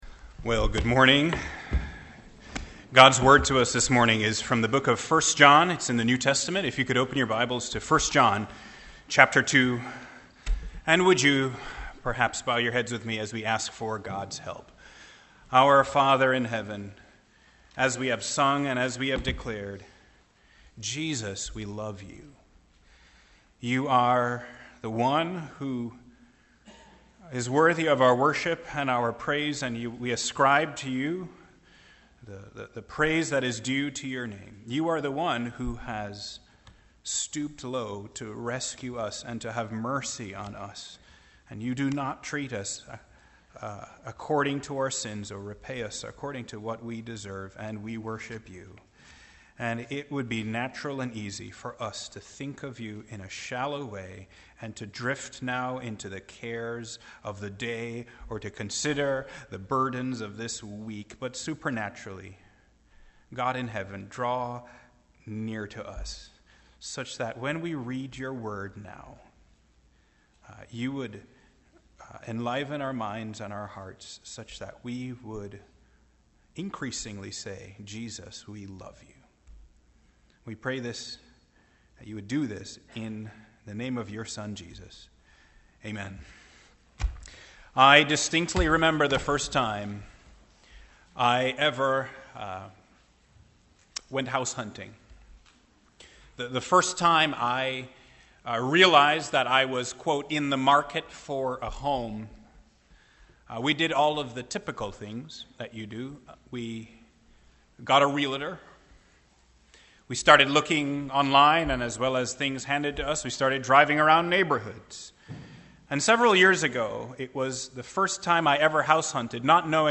1 John — Audio Sermons — Brick Lane Community Church